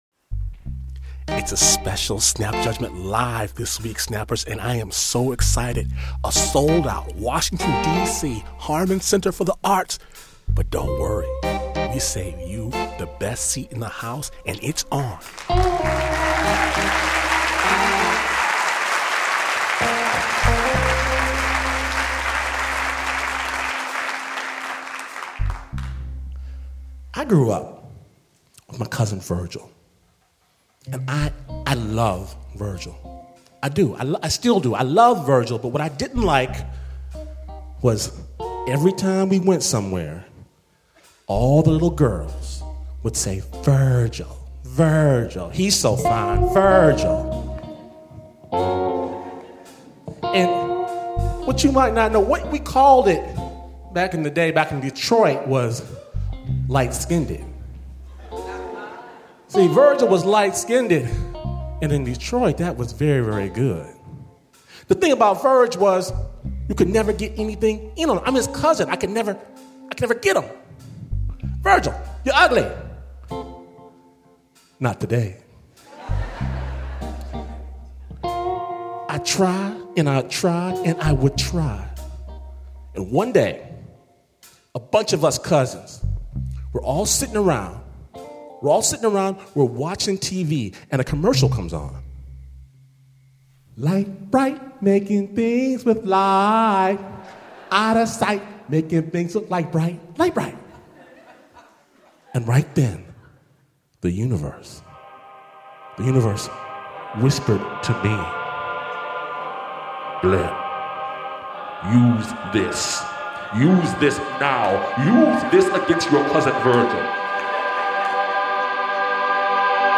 This week, enjoy the best seat in the house as Glynn Washington and the nation’s finest storytellers rock Washington DC’s Harman Center with a sold-out show before a screaming audience.